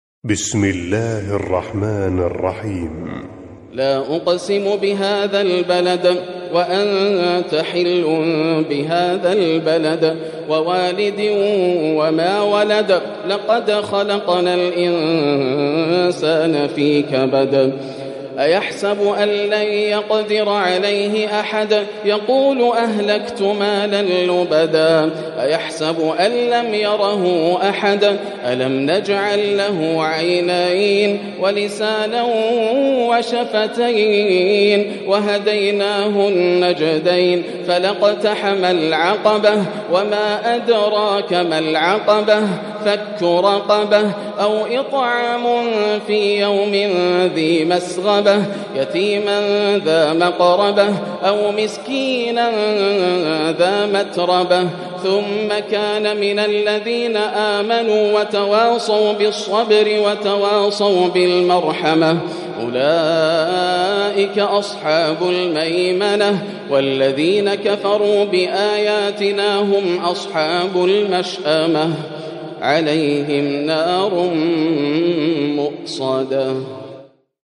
سورة البلد من تهجد رمضان 1441هـ > السور المكتملة > رمضان 1441هـ > التراويح - تلاوات ياسر الدوسري